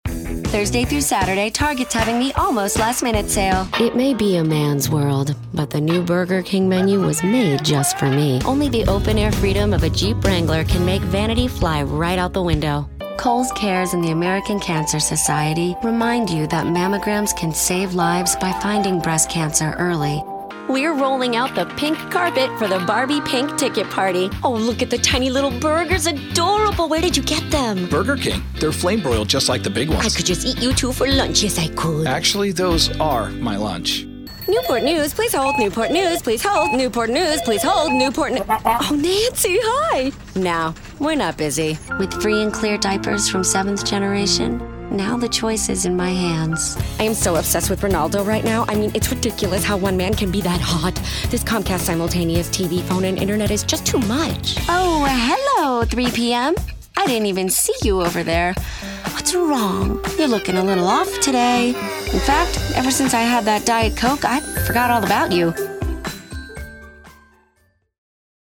Has Own Studio
english Accents: standard us | natural Voice Filters
COMMERCIAL